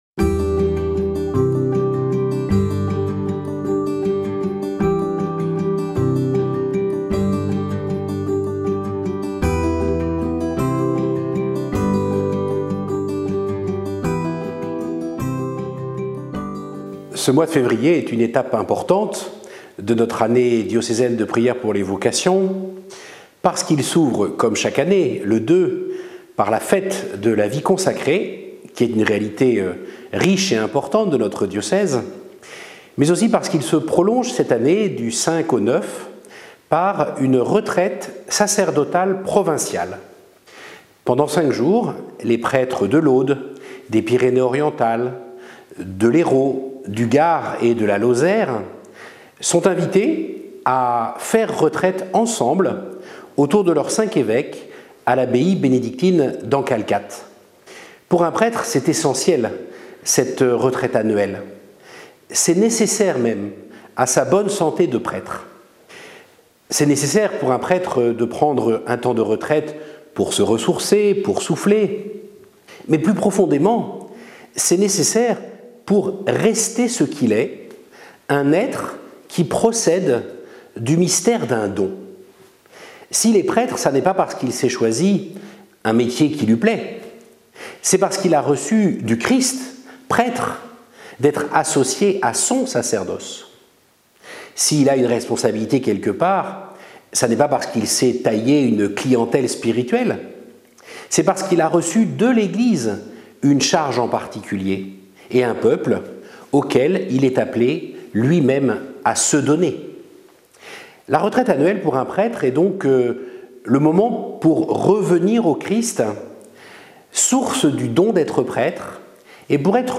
Nous sommes invités à prier pour les prêtres que nous souhaitons que le Seigneur nous donne, mais aussi pour ceux que nous avons déjà. Écoutons et partageons les mots de notre évêque, Mgr Bruno Valentin, dans le "mot de l'évêque" de ce nouveau mois.